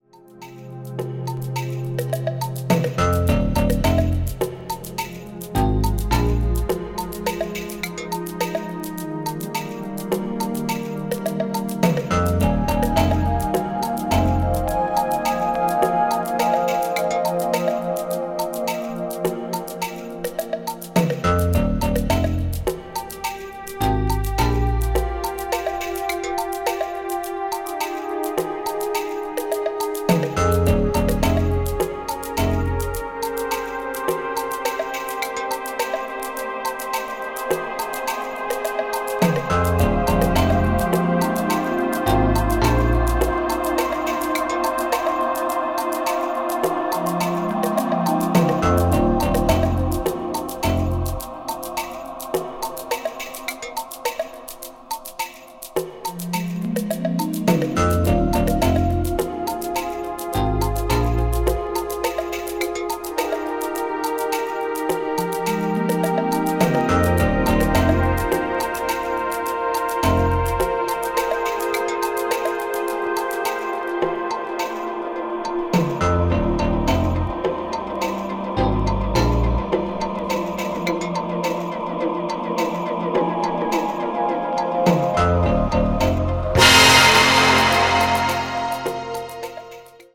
ambient   electronic   new age   synthesizer